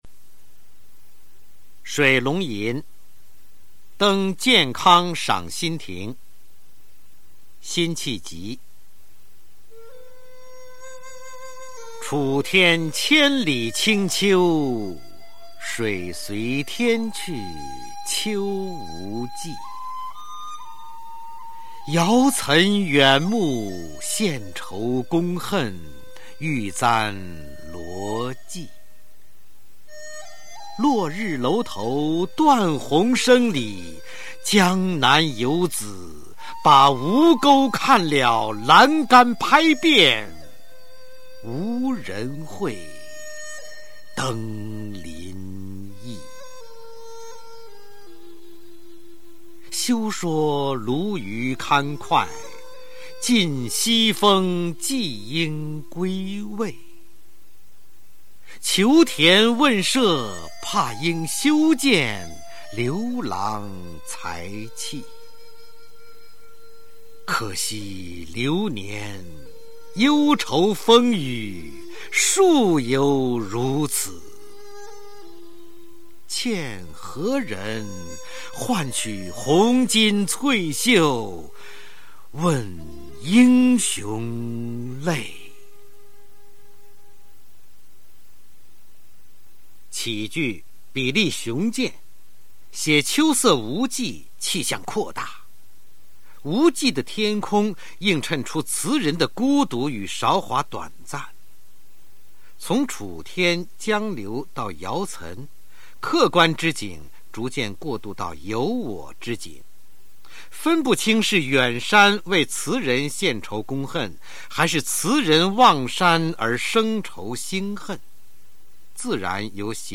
《水龙吟登建康赏心亭》音频朗诵